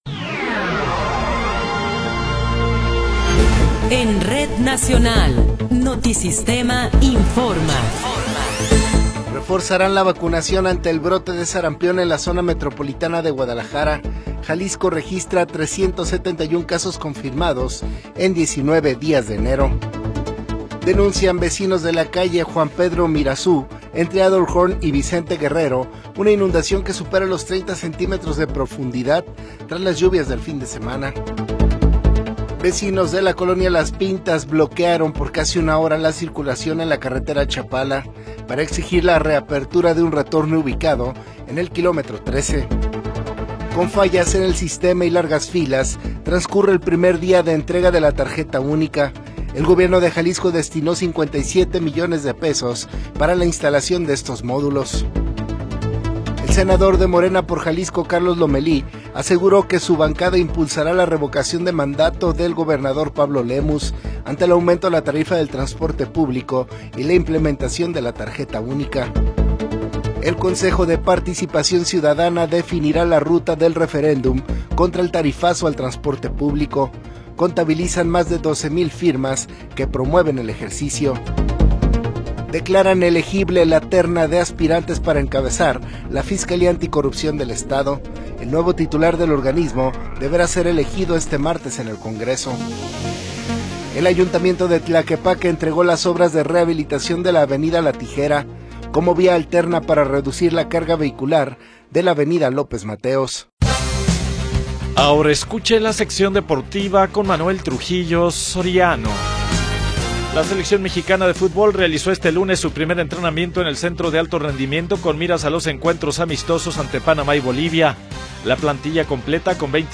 Noticiero 21 hrs. – 19 de Enero de 2026
Resumen informativo Notisistema, la mejor y más completa información cada hora en la hora.